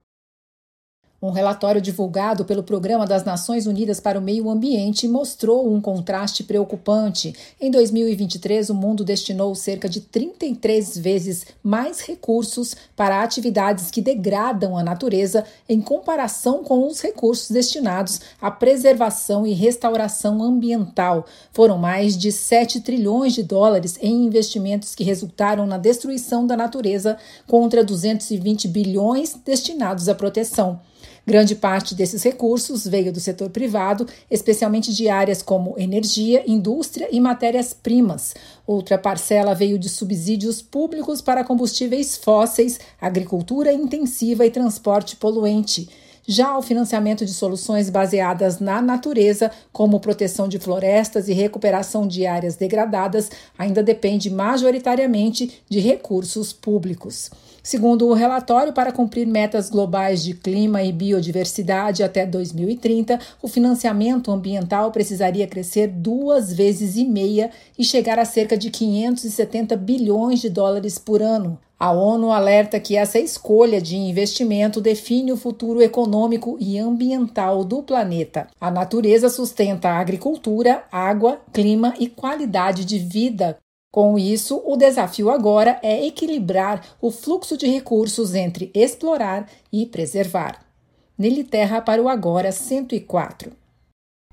A repórter